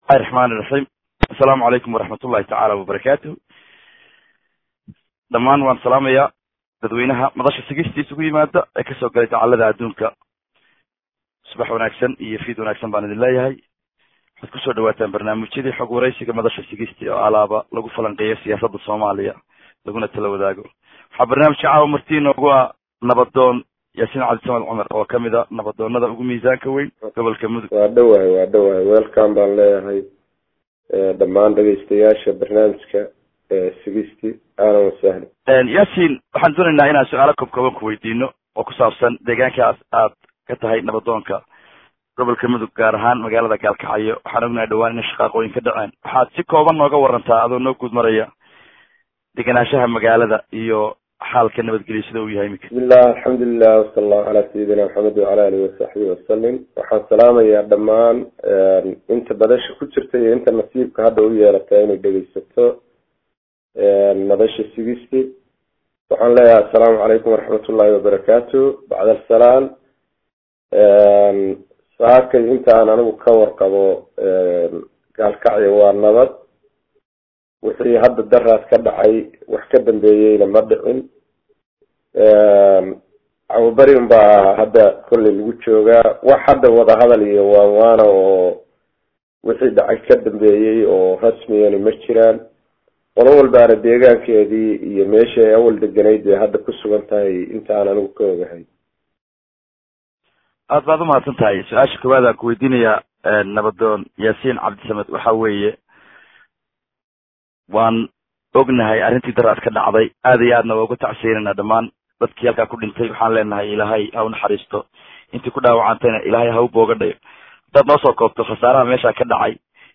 Waraysiga oo ahaa mid kiiska gardaro ee dagaalka ku kuusay maamulka Galmudug. iyada oo la sugayo waraysiga dhinaca Galmudug.